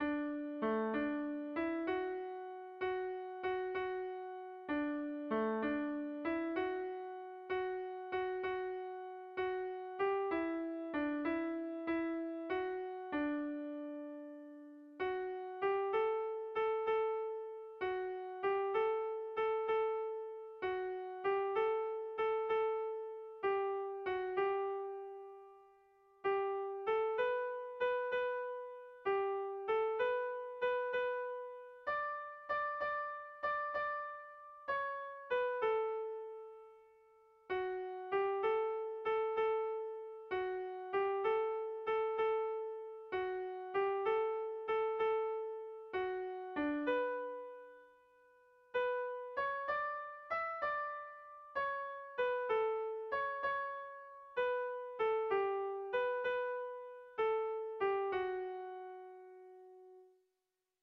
Kontakizunezkoa
Bertsolari
Hamaikakoa, handiaren moldekoa, 7 puntuz (hg) / Zazpi puntukoa, handiaren moldekoa (ip)
AABDE..